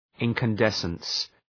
Προφορά
{,ınkən’desəns}